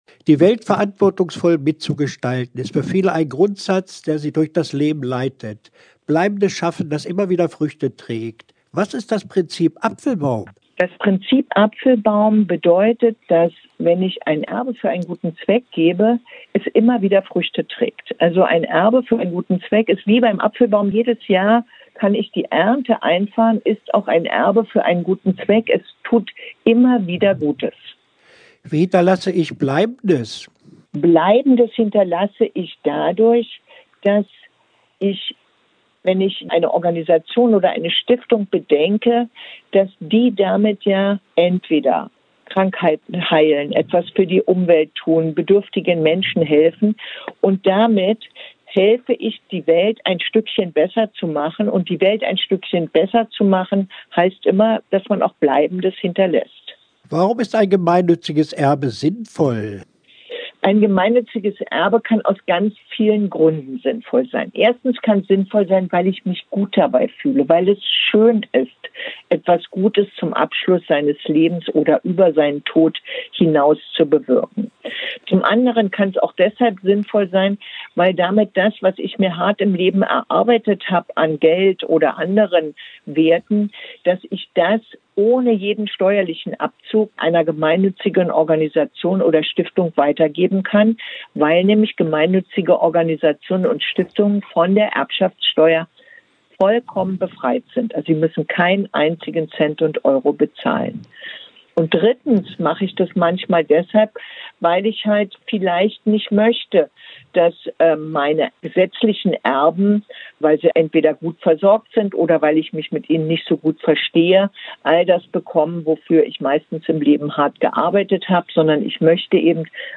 Interview-Prinzip-Apfelbaum.mp3